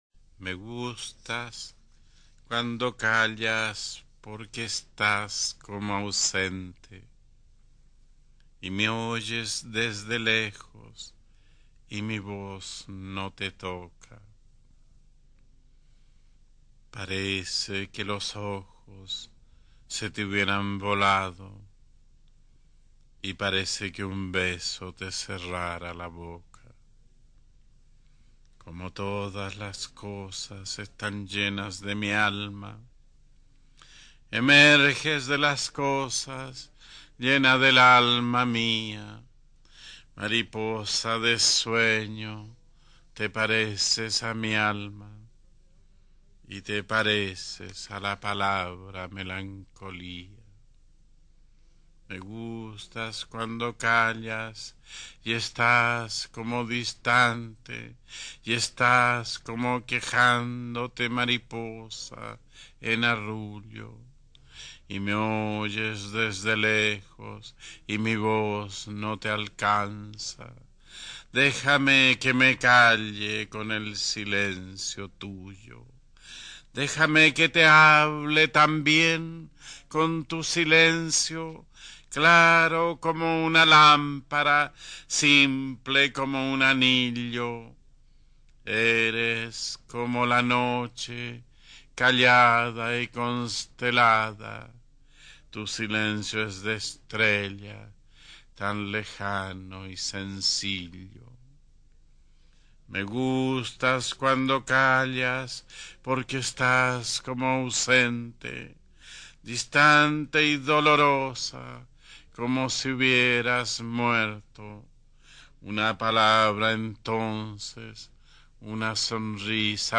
Читает Пабло Неруда